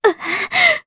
Juno-Vox_Damage_jp_01.wav